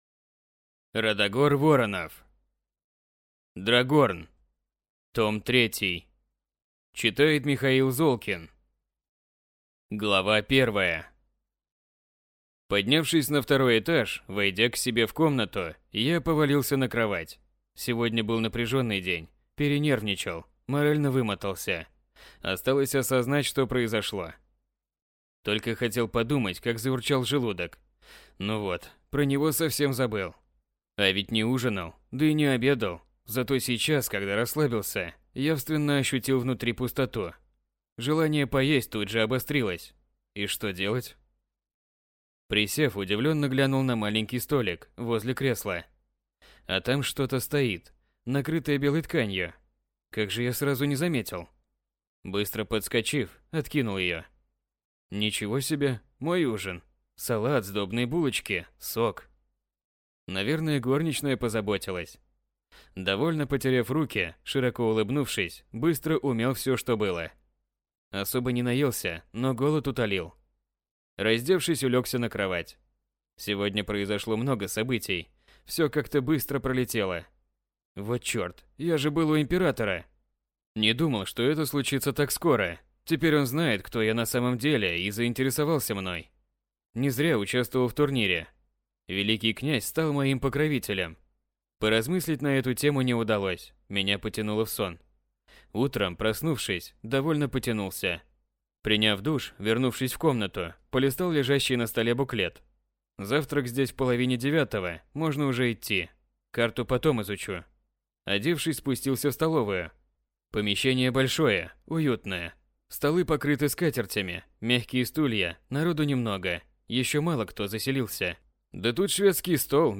Аудиокнига Драгорн. Том III | Библиотека аудиокниг